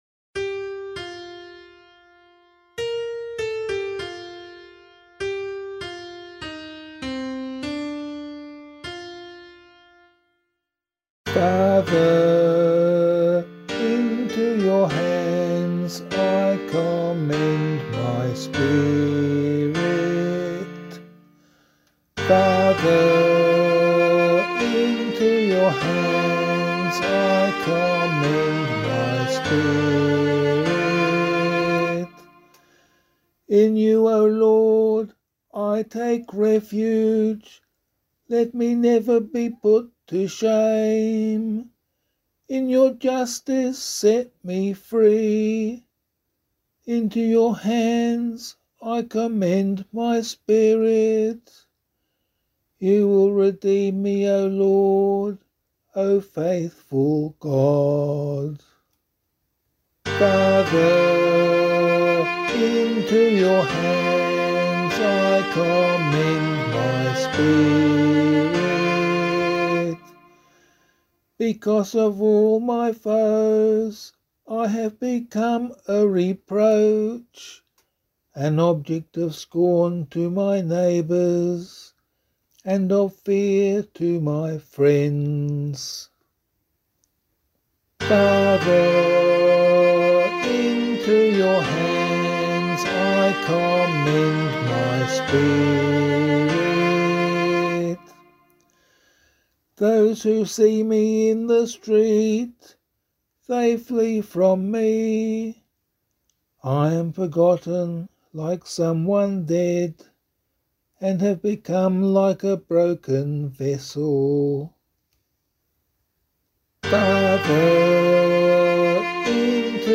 021 Good Friday Psalm [APC - LiturgyShare + Meinrad 3] - vocal.mp3